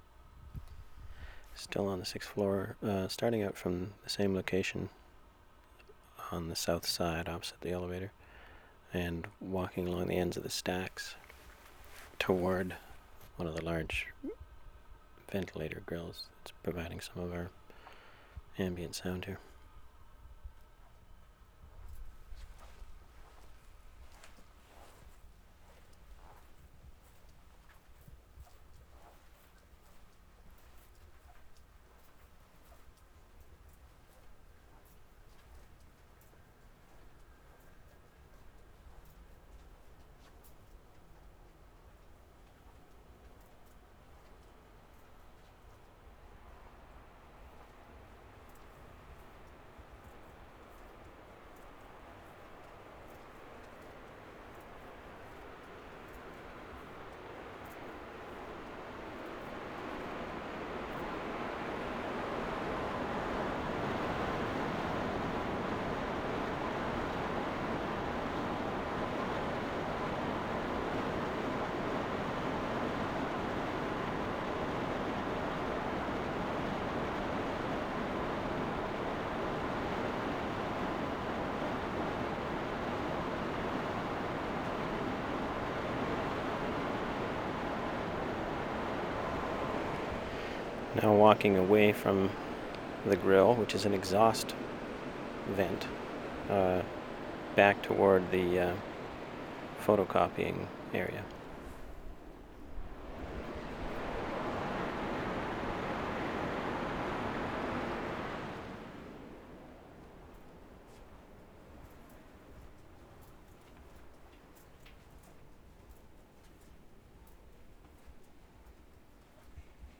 VANCOUVER SOUNDSCAPE, JUNE, 1996
library, 6th floor, walk toward ventilator 4:48
2. ID, footsteps of recordist, gradual increase of ventilation sound. Close-up of ventilator at 1:10. Tape ID at 1:32. Walk toward photocopiers at 1:47, hum of machines becomes more apparent at 2:45. 3:50, close-up of machine. Quiet again at 4:11. Tape ID at 4:24